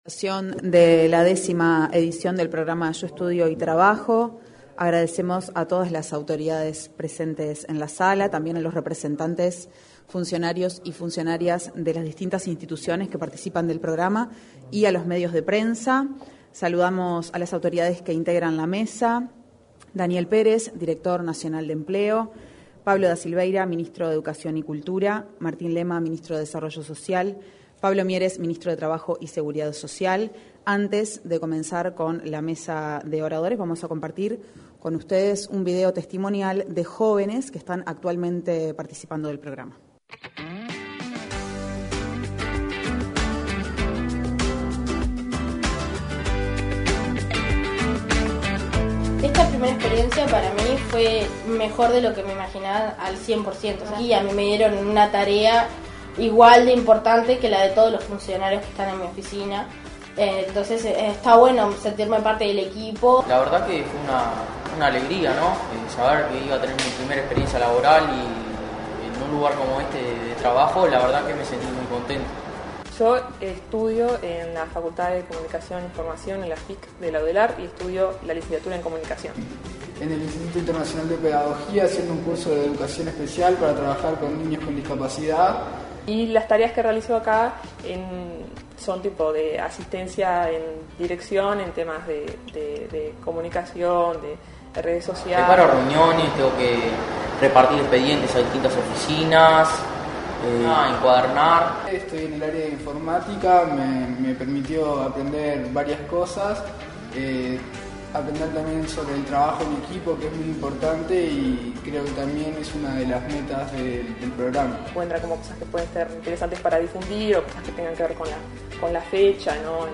Lanzamiento de la décima Edición del Programa Yo Estudio y Trabajo 23/11/2021 Compartir Facebook X Copiar enlace WhatsApp LinkedIn Este martes 23 de noviembre, se realizo el lanzamiento de la décima edición del programa Yo Etudio y Trabajo. En el evento participaron los ministros de Trabajo y Seguridad Social, Pablo Mieres; Educación y Cultura, Pablo da Silveira, y Desarrollo Social, Martin Lema, y el director nacional de Empleo, Daniel Pérez.